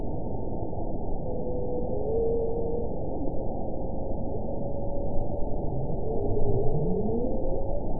event 922419 date 12/31/24 time 21:40:00 GMT (4 months ago) score 8.84 location TSS-AB06 detected by nrw target species NRW annotations +NRW Spectrogram: Frequency (kHz) vs. Time (s) audio not available .wav